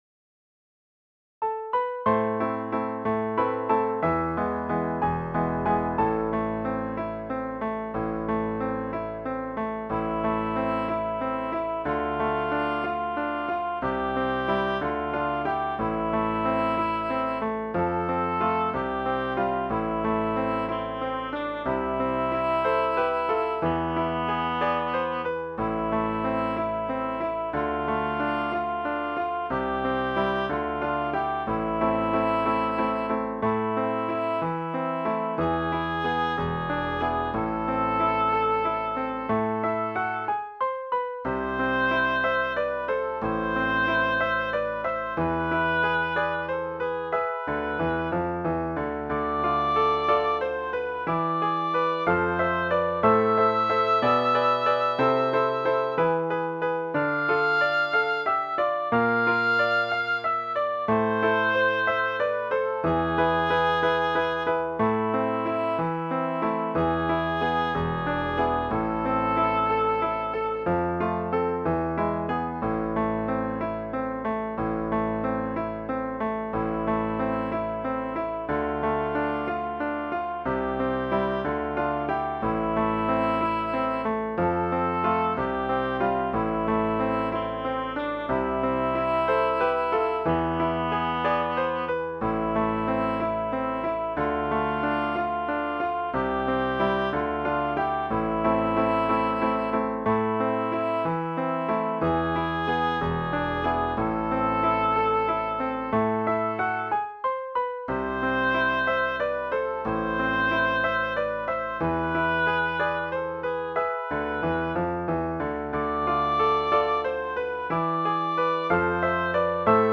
Соло сопрано или тенор (ля-минор, си-минор)